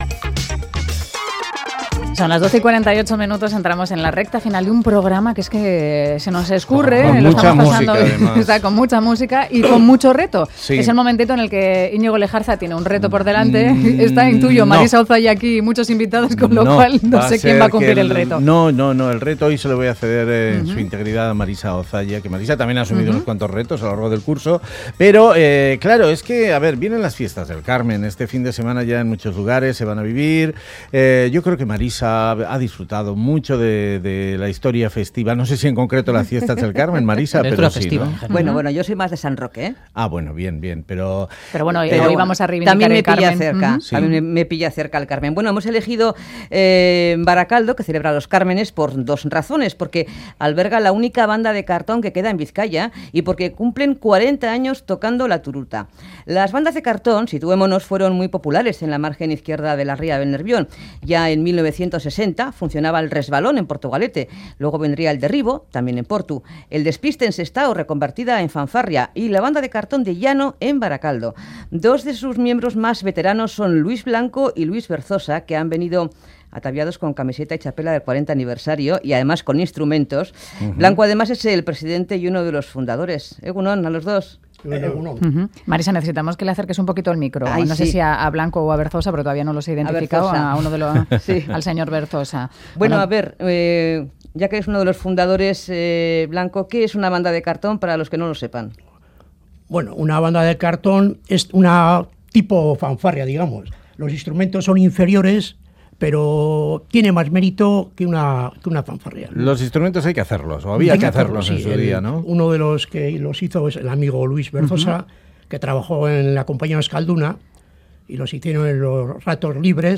Nos explican cómo fabrican sus instrumentos artesanalmente y nos ofrecen una muestra de su arte.